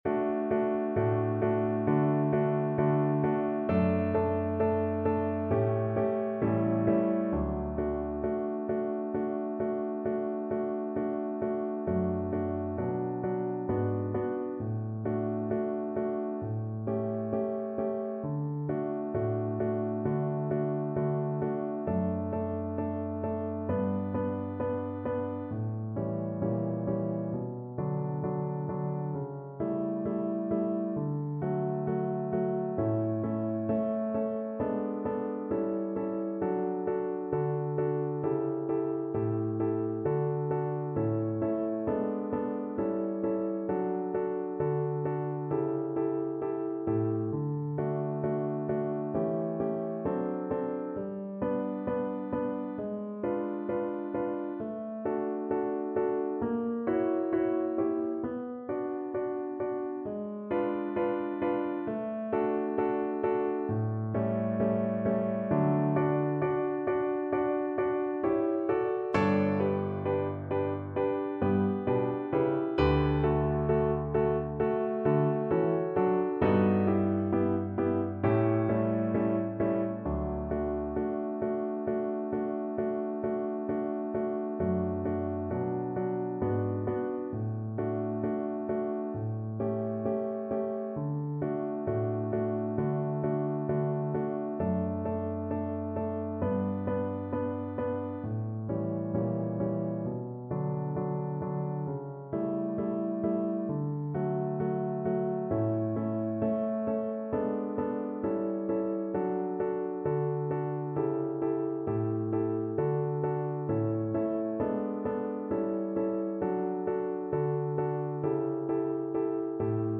Voice Classical
Piano Playalong MP3